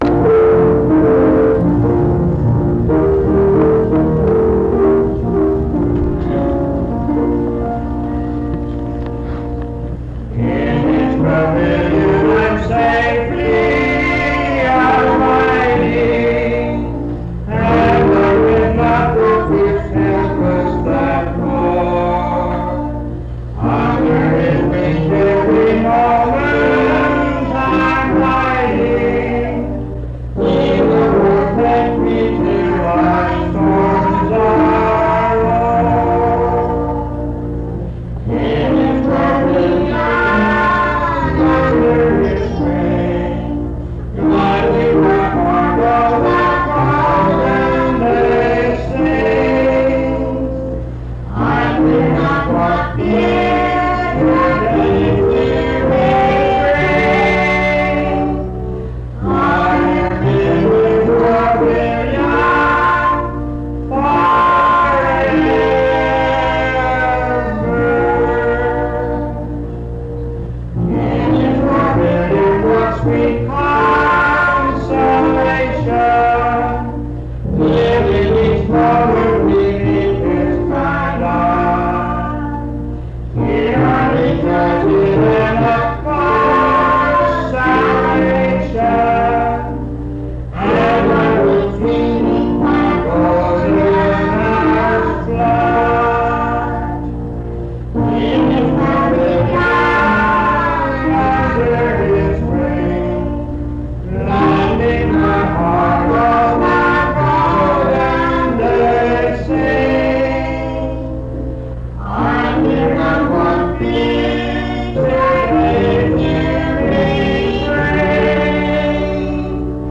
This recording is from the Monongalia Tri-District Sing. Highland Park Methodist Church, Morgantown, Monongalia County, WV.